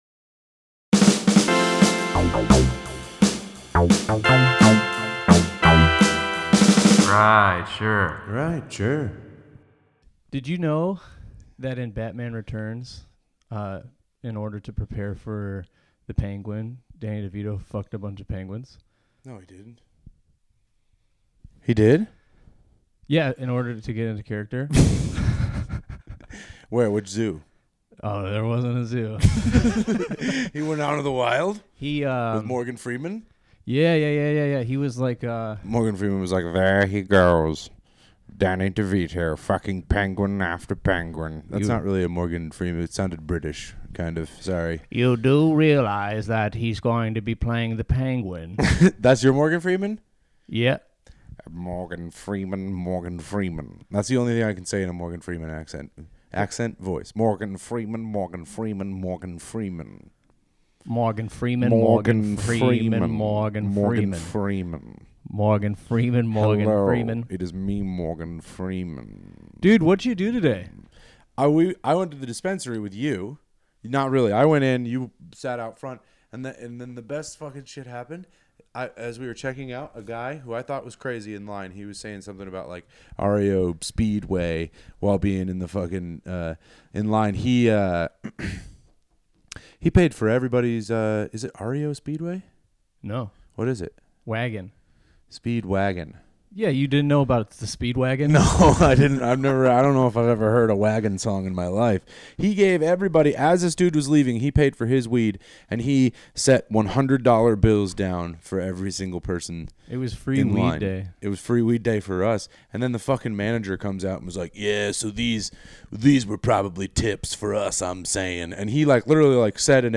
The dumbest podcast hosted by two absolute morons.
From half-cocked, incorrect takes on current events to terrible impressions, this podcast will make you dumber.